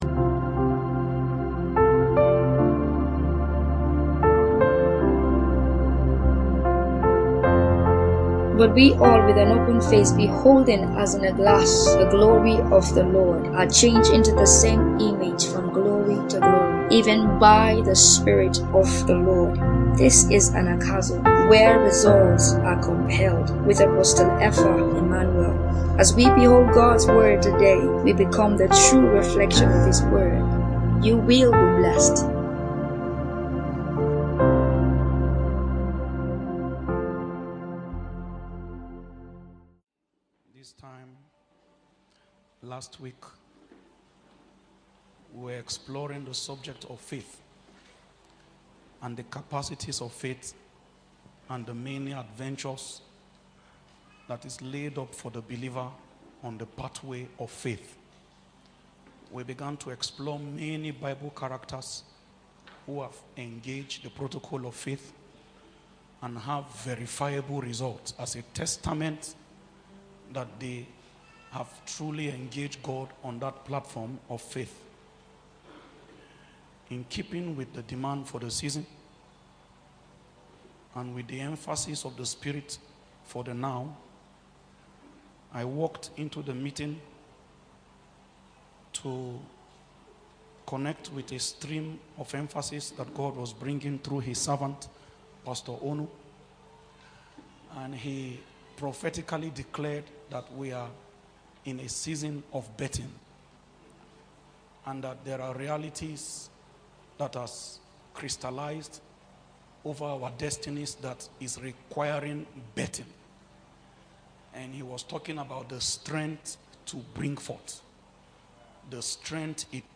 Sermon | Anagkazo Mission International | Anagkazo Mission International